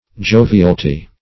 jovialty - definition of jovialty - synonyms, pronunciation, spelling from Free Dictionary Search Result for " jovialty" : The Collaborative International Dictionary of English v.0.48: Jovialty \Jo"vi*al*ty\, n. Joviality.